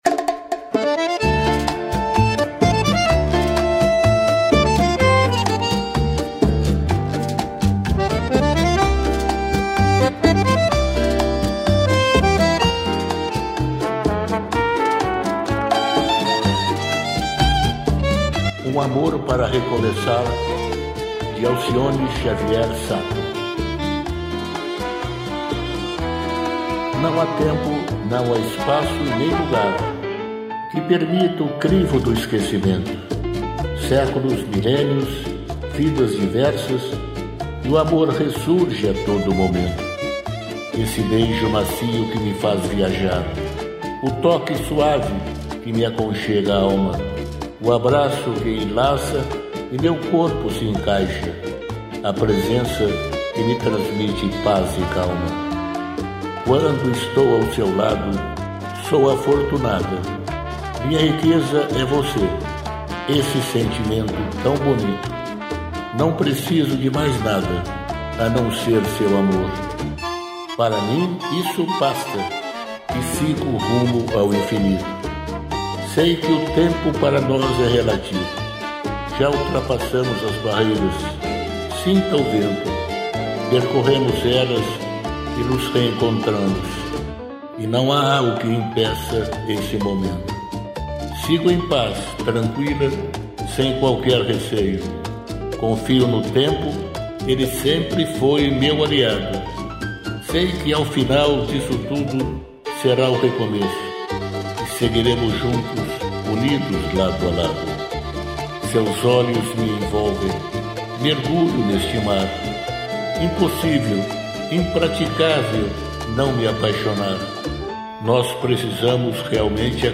CD- 133 - Músicas com a utilização de IA - Outorga
música, arranjo: IA